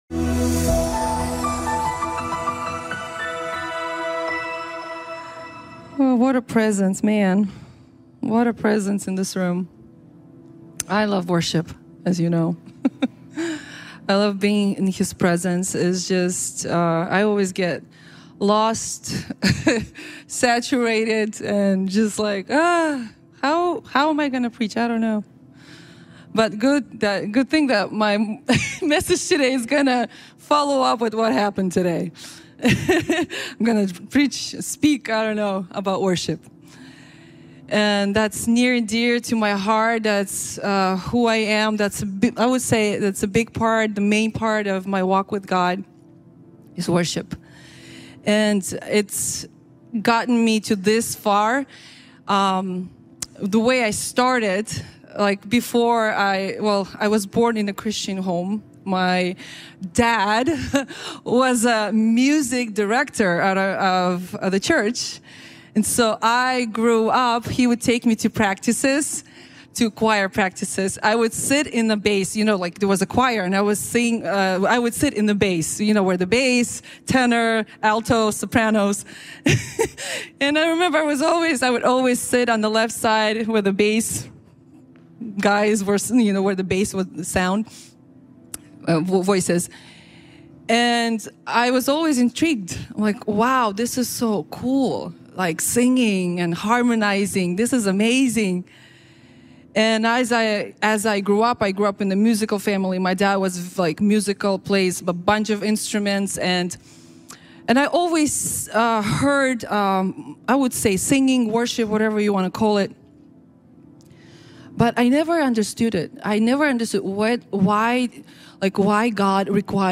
Проповеди пастора